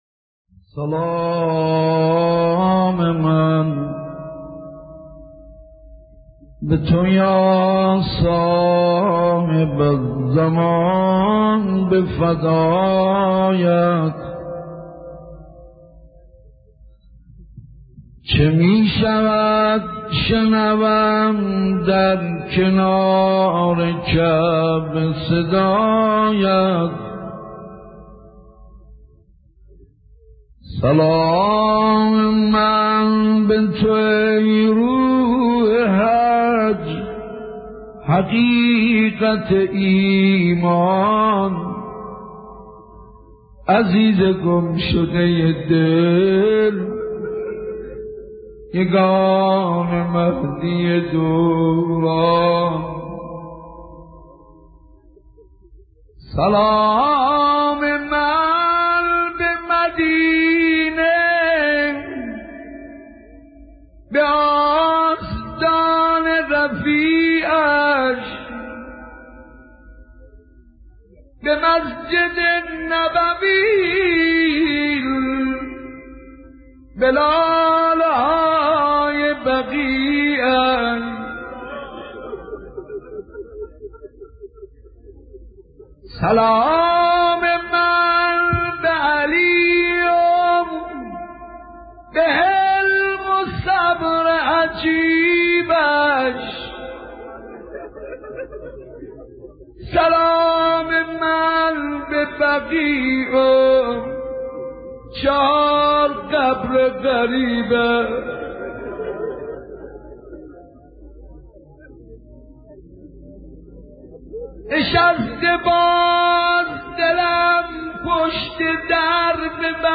مداحان و ذاکران اهل بیت که از روزهای قبل به مناسبت شهادت حضرت زهرا(س) با حضور در مساجد و حسینیه ها به مرثیه سرایی و روضه خوانی پرداخته اند، در ایام فاطمیه نیز اشعار و سروده هایی را در رثای بانوی دو عالم خوانده اند که در اینجا با بارگذاری بعضی از این مداحی ها، حال و روزمان را فاطمی می کنیم.